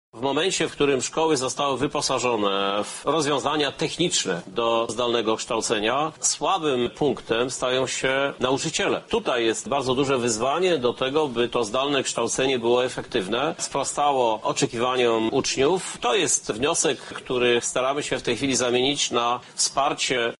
Jeśli sobie uświadomimy, że nauczanie zdalne może być permanentnym modelem, musimy porozumieć się z rodzicami i nauczycielami – mówi prezydent Lublina Krzysztof Żuk: